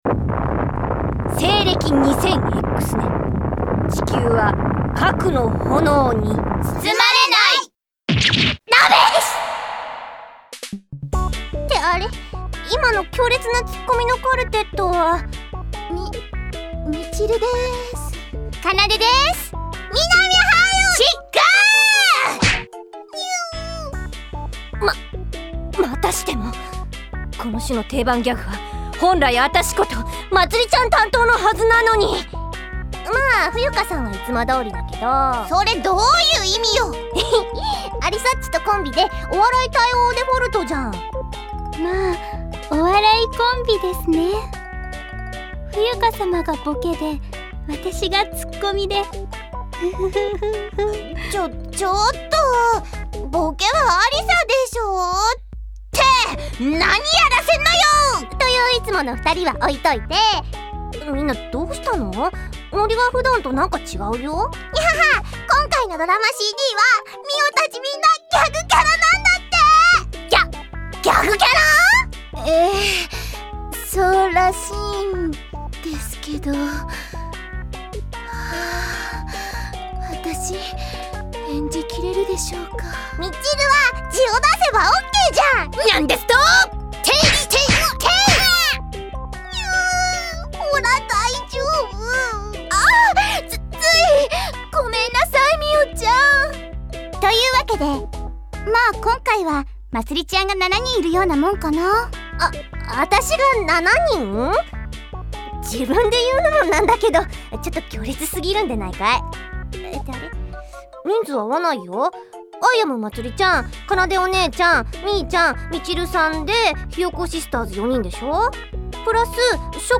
ドラマちっくCD 楽しい嬉しい宣伝ボイス